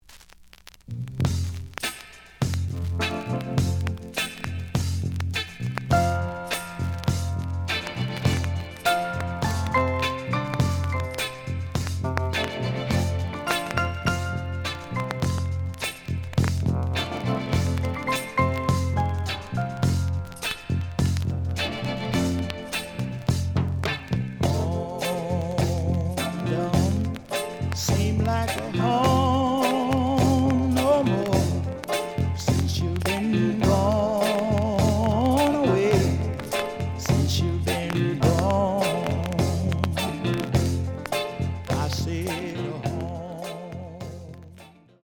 The audio sample is recorded from the actual item.
●Genre: Soul, 70's Soul
Some noise on B side.)